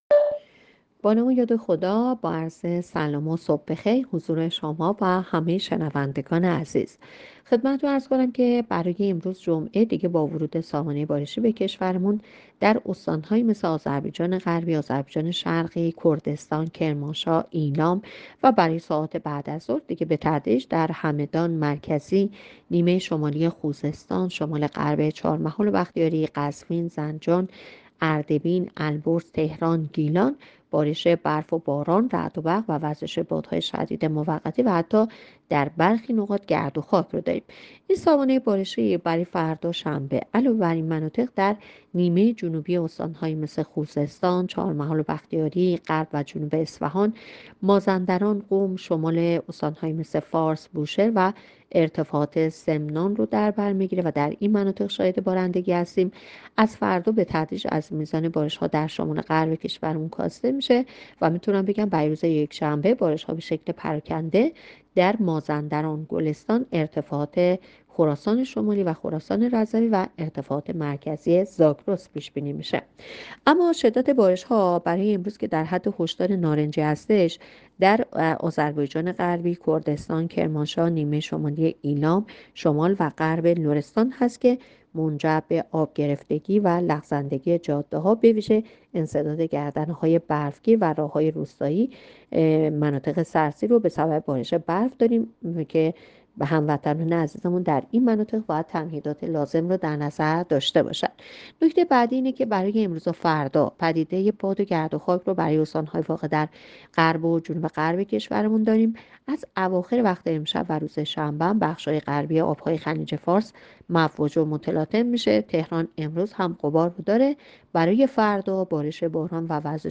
گزارش رادیو اینترنتی پایگاه‌ خبری از آخرین وضعیت آب‌وهوای ۷ دی؛